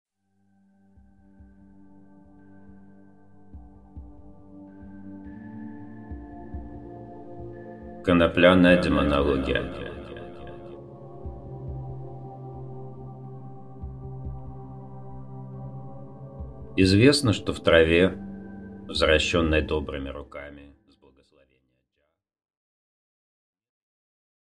Аудиокнига Предисловие | Библиотека аудиокниг